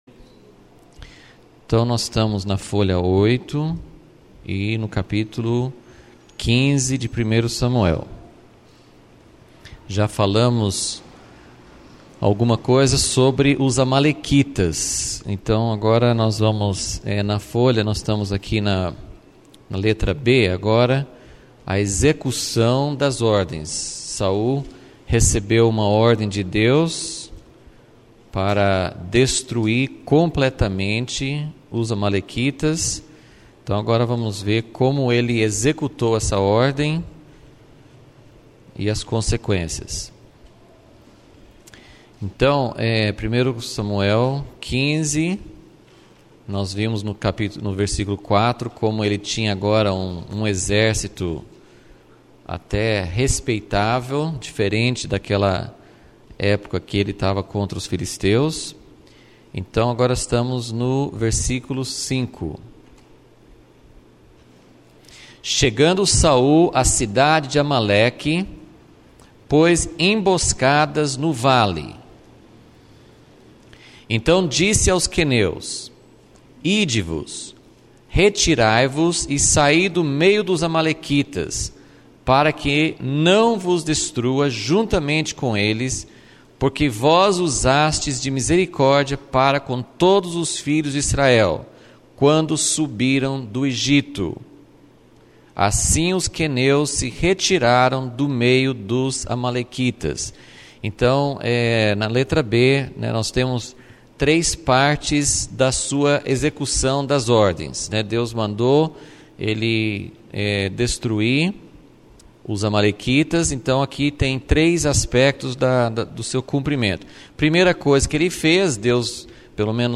Aula 13 – ESB Vol.10 – Deus chora por Saul